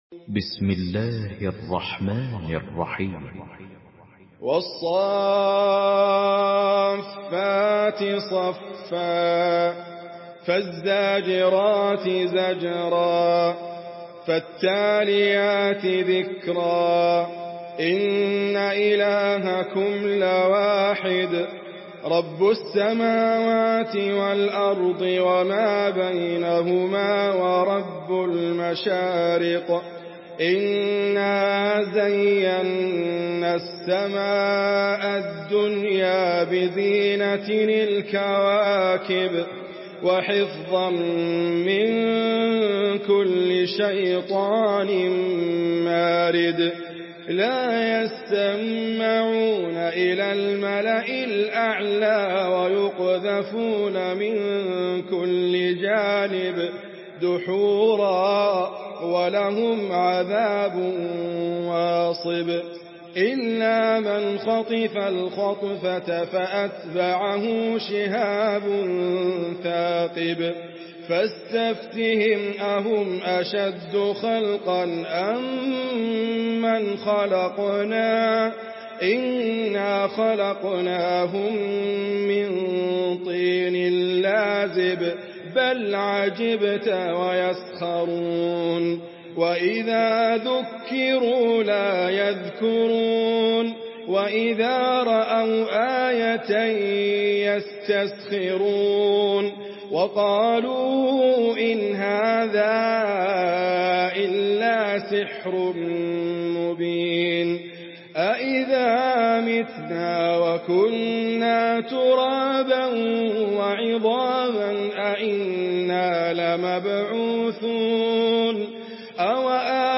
Idriss Abkar mp3 Murattal Hafs An Asim